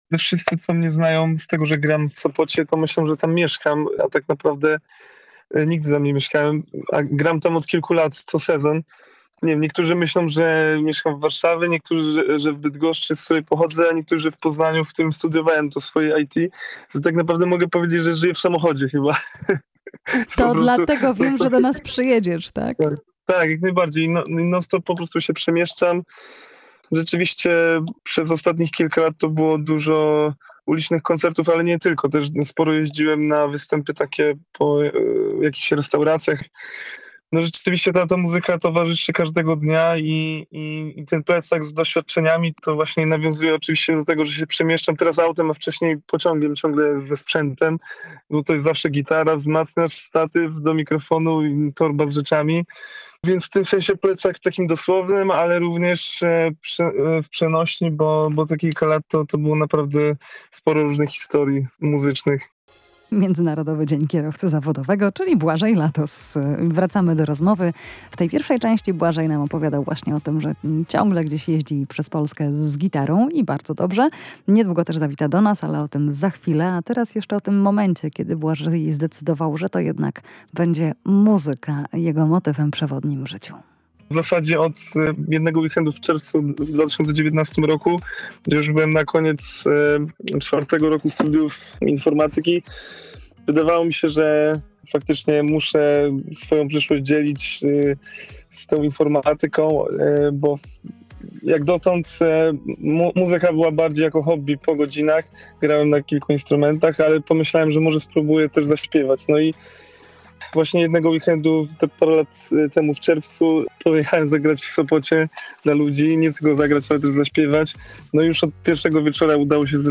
marzenia i wyzwania muzyczne [POSŁUCHAJ ROZMOWY]